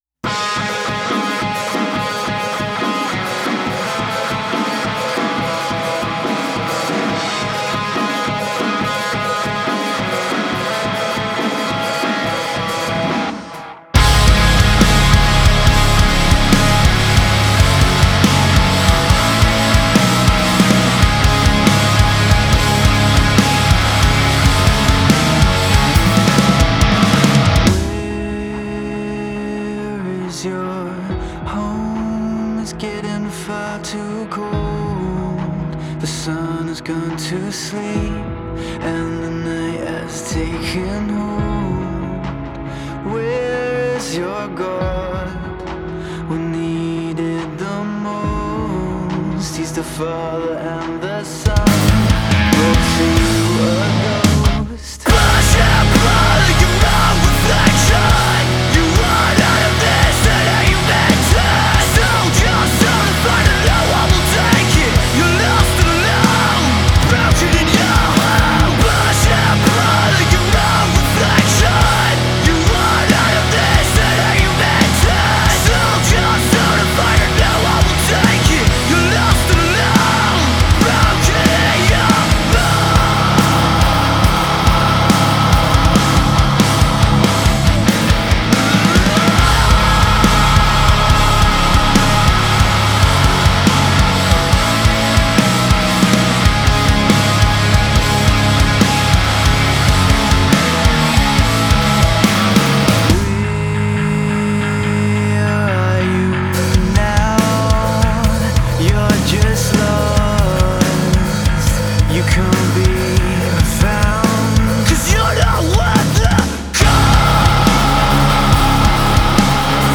metalcore band